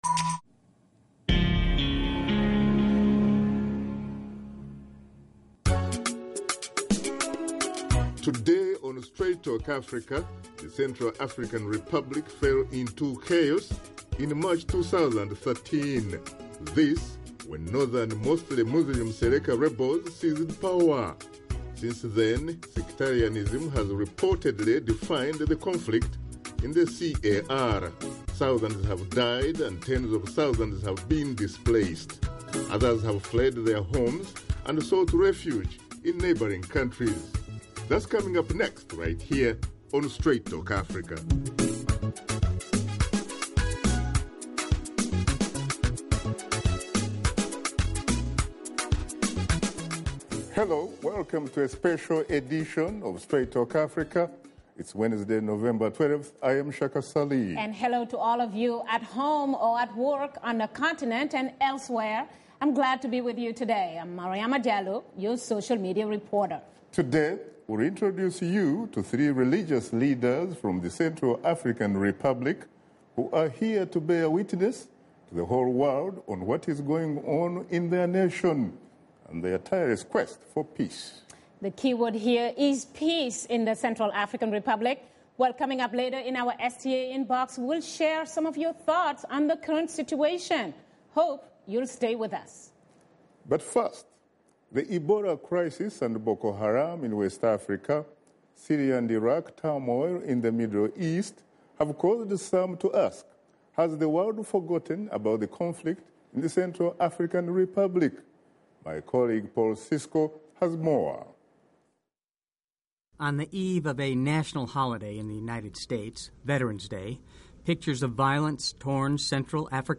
Host Shaka Ssali sits down with religious leaders from Central Africa Republic who are committed to peacebuilding from an inter-faith perspective to end the violence. Guests: Imam Omar Kabine Layama, President, Central African Islamic Community; Archbishop Dieudonné Nzapalainga, Catholic Archbishop of Bangui; and Reverend Nicolas Guérékoyame Gbangou, President of the Evangelical Alliance of the Central African Republic